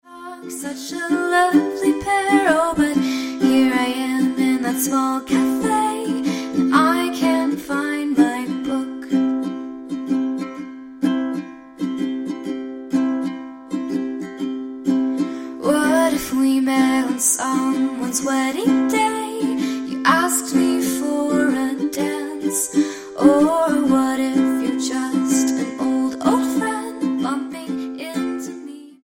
Style: MOR/Soft Pop Approach: Praise & Worship